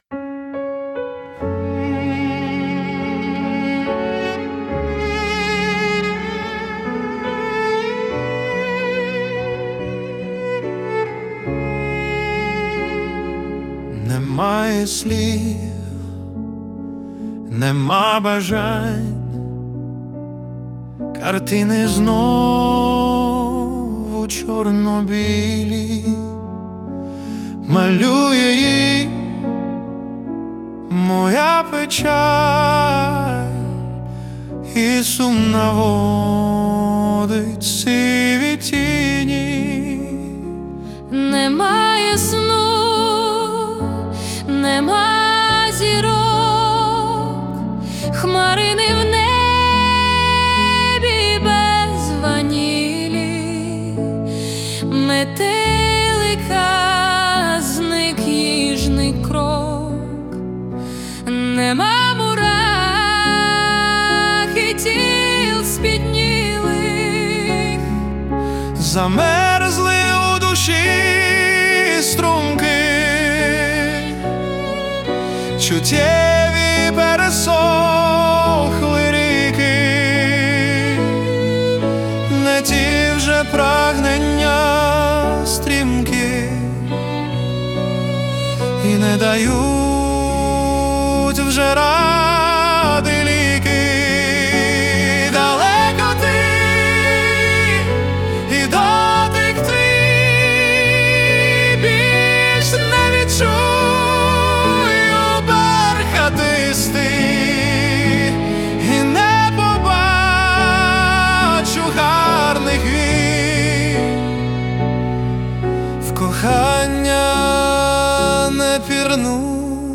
Музика і вокал ШІ - SUNO AI v4.5+
СТИЛЬОВІ ЖАНРИ: Ліричний